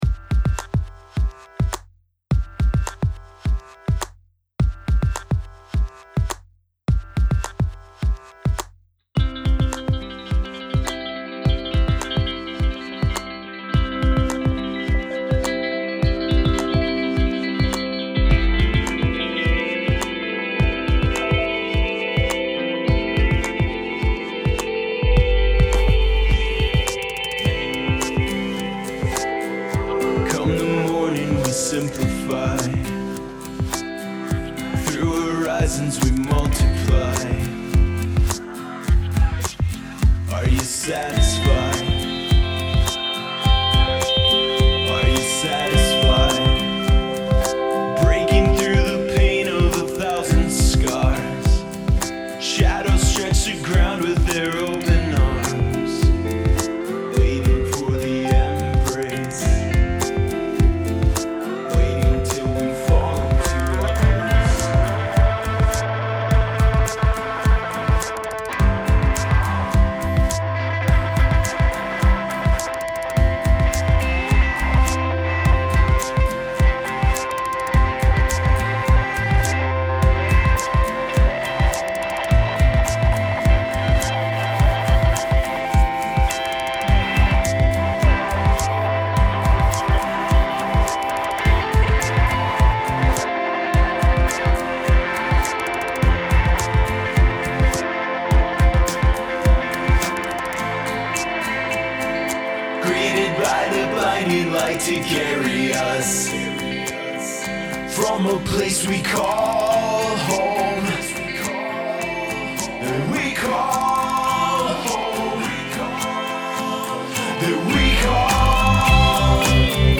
A series of experimental recordings including.